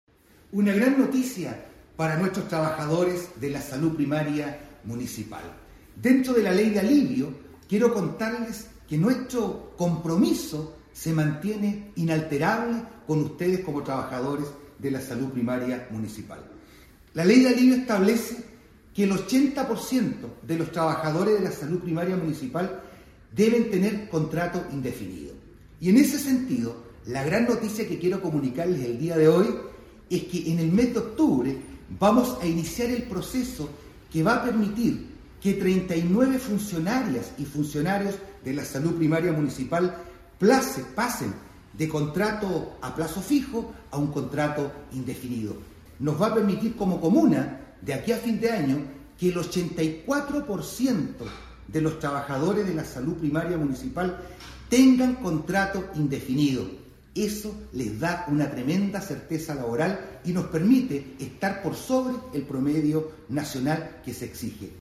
Así lo informó, el alcalde Juan Eduardo Vera: